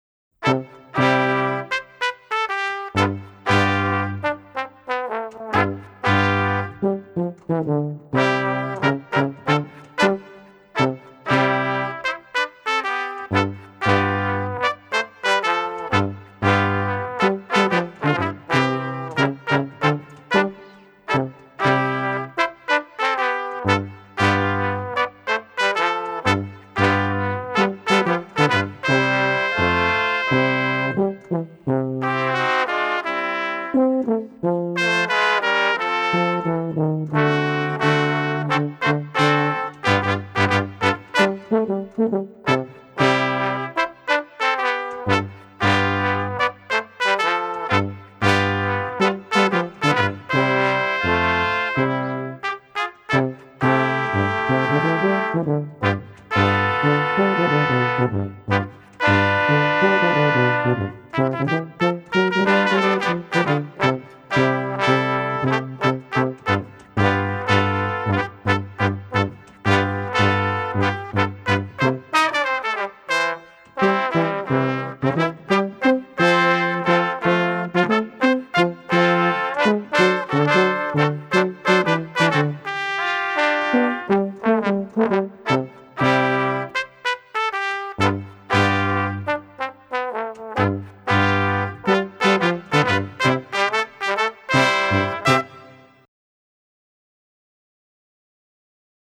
Gattung: 4 Blechbläser
Besetzung: Ensemblemusik für 4 Blechbläser